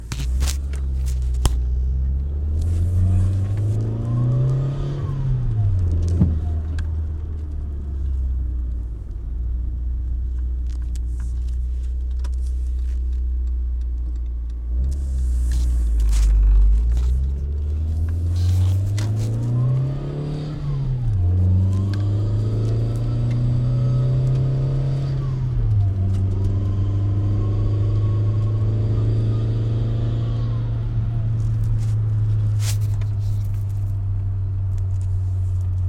Whine Under Acceleration
When I'm under acceleration I get this whining noise coming from the engine bay it sounds like its from the driver's side. its loudest when the car is cold, and gets quieter as the car warms but I can still clearly hear it. It sounds almost like a supercharger.